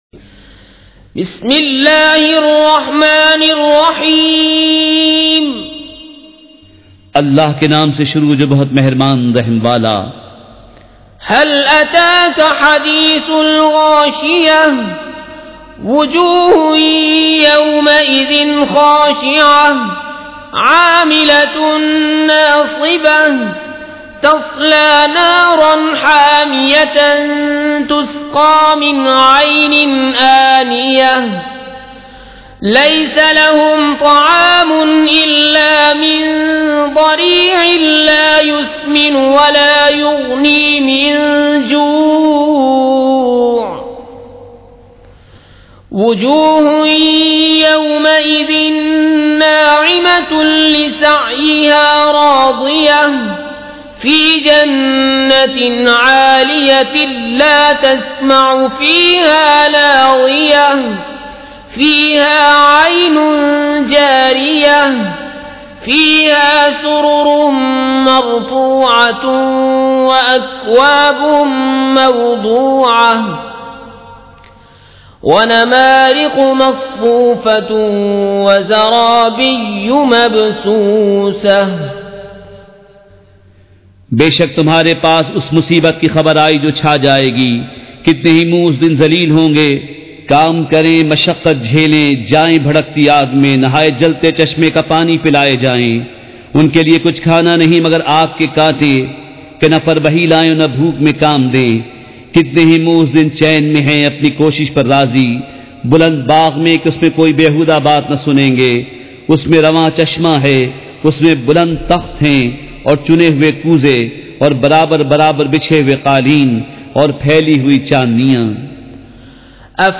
سورۃ الغاشیۃ مع ترجمہ کنزالایمان ZiaeTaiba Audio میڈیا کی معلومات نام سورۃ الغاشیۃ مع ترجمہ کنزالایمان موضوع تلاوت آواز دیگر زبان عربی کل نتائج 2695 قسم آڈیو ڈاؤن لوڈ MP 3 ڈاؤن لوڈ MP 4 متعلقہ تجویزوآراء